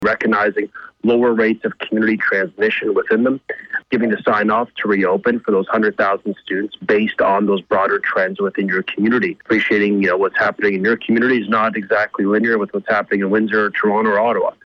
Minister of Education Stephen Lecce